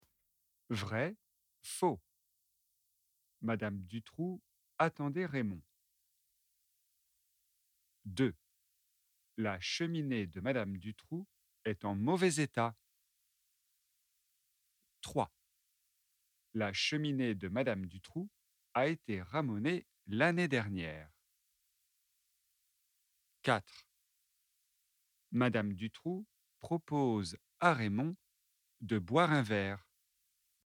🔷DIALOGUE :